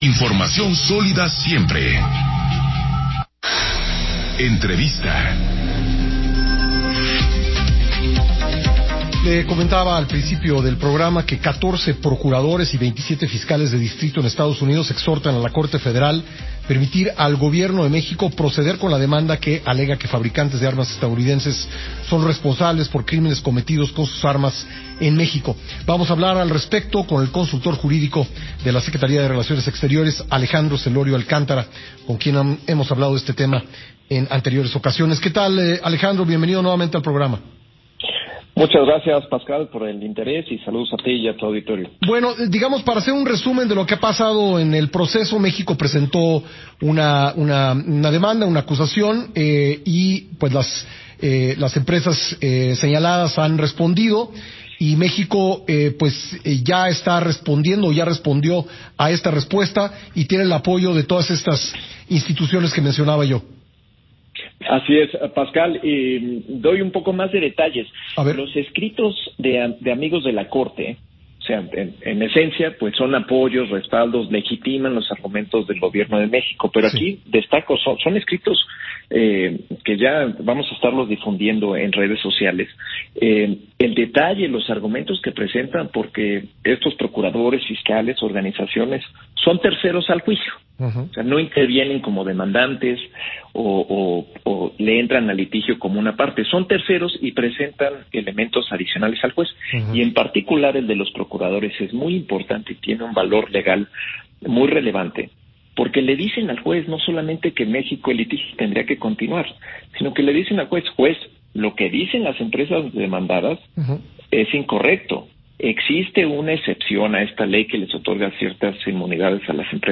Interviews of SRE officials